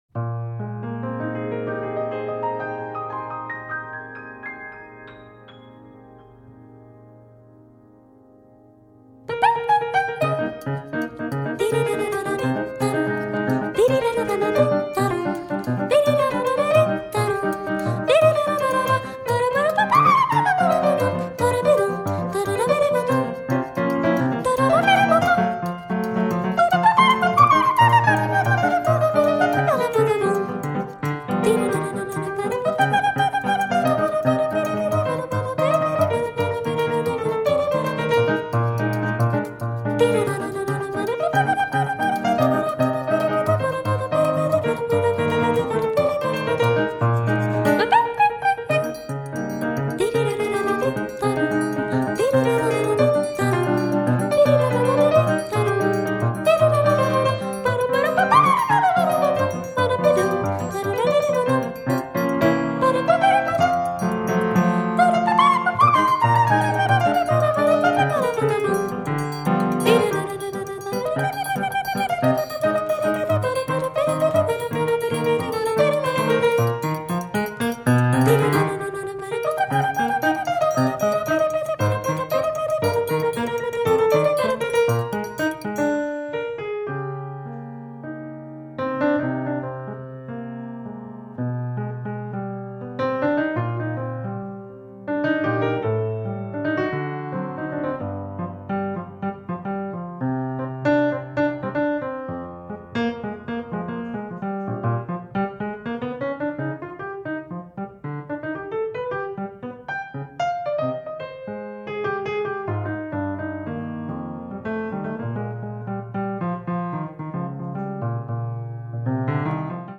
ジャズを基調にかなり自由に突き抜けた世界を展開していますね！
前衛的な要素を持ちながらも全てポップ・ミュージックに昇華してしまうあたりも素晴らしいですね！